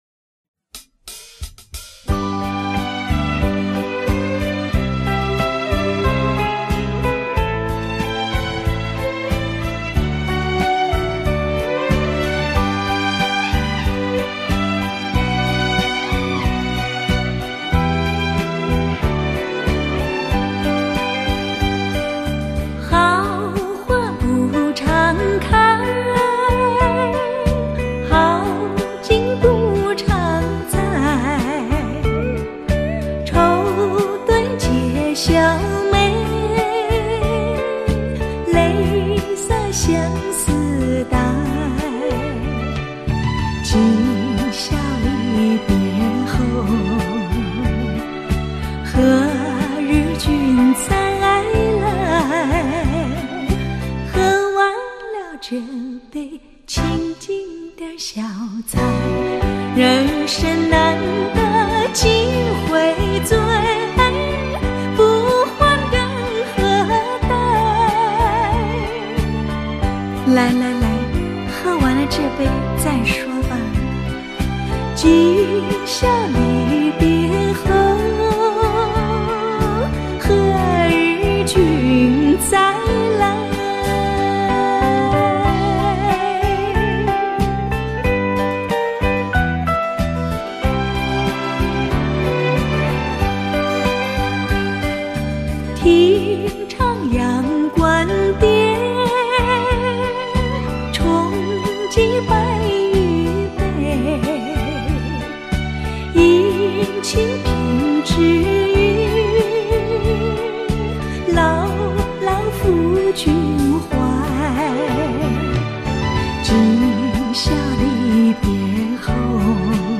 Popular Chinese Song